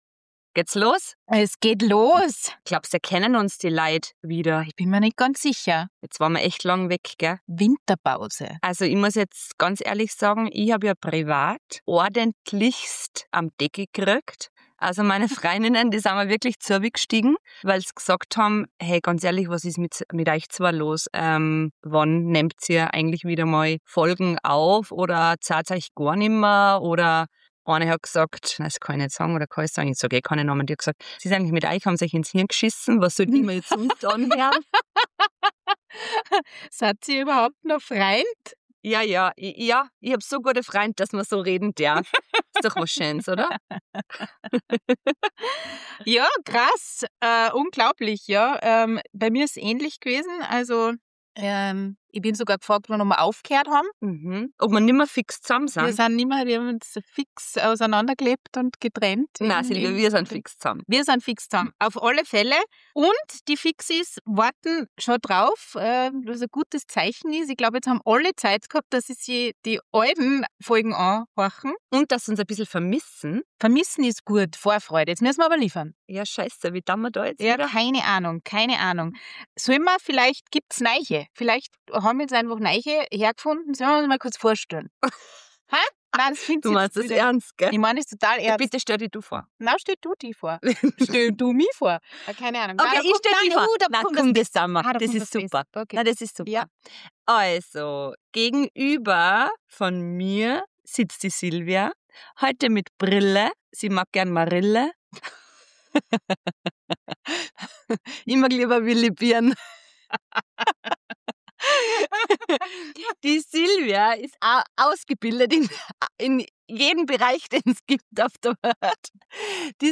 Winterhochzeiten & Skitage Pistenraupe als Brautauto Hochzeitsgäste ohne Gepäck Sarah-Connor-Fangirl-Moment Weihnachts-Nervenzusammenbruch Die beiden geben Einblicke in den privaten Alltagswahnsinn, reflektieren über Stress und Traditionen und blicken optimistisch auf die kommende Saison. Außerdem sprechen die beiden über Dialekt bei Trauungen, Podcast-Routinen, Valentinstag-Anfragen und warum 2027 schneller kommt als gedacht. Eine Folge voller Lacher, Ehrlichkeit und Wedding-Vibes.